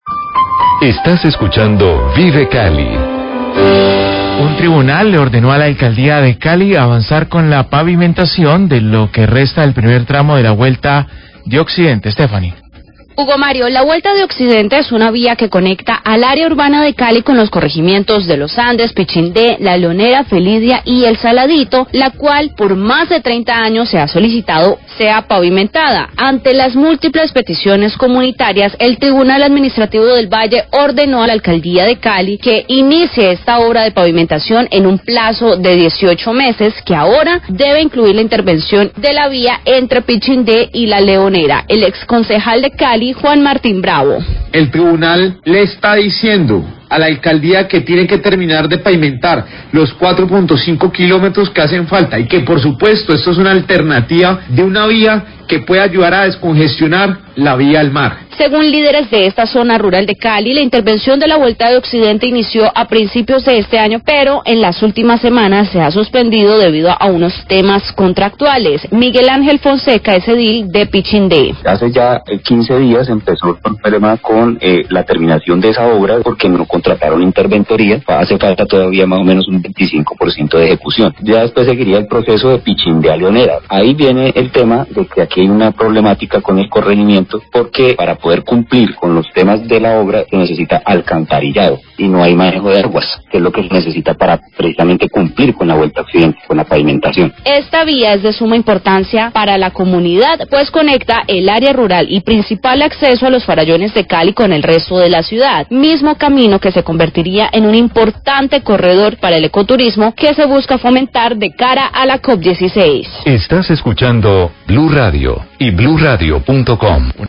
Radio
Debido a una acción popular interpuesta por el exconcejal Juan Martín Bravo, quien habla del tema.